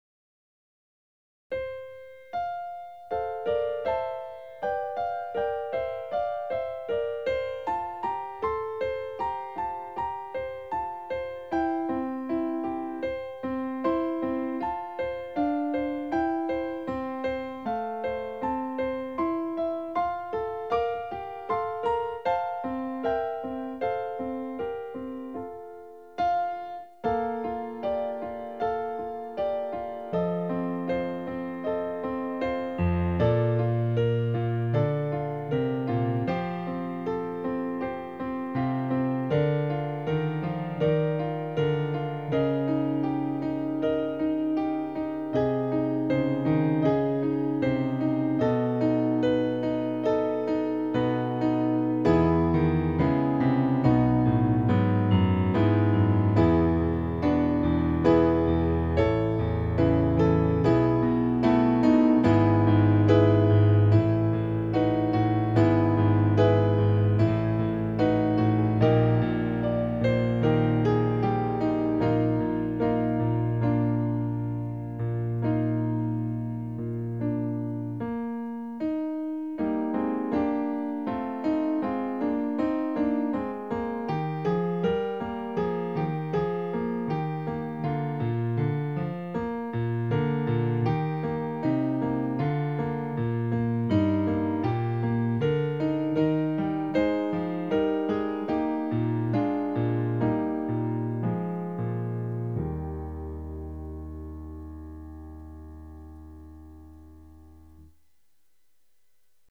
Piano Solo
Voicing/Instrumentation: Piano Solo We also have other 3 arrangements of " Come We That Love the Lord ".